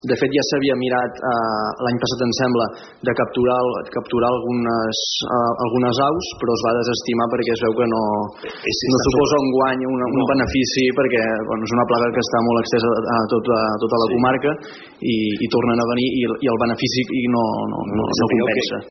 Al ple ordinari d’aquest mes d’octubre, la regidora de Palafolls en Comú, Clara Hidalga, va preguntar al govern municipal sobre l’existència d’una colònia d’aquestes aus al nostre municipi.